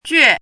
怎么读
juè jué
jue4.mp3